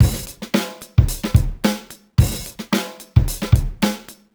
Index of /musicradar/sampled-funk-soul-samples/110bpm/Beats